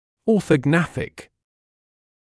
[ˌɔːθəˈgnæθɪk][ˌо:сэ’гнэсик]ортогнатический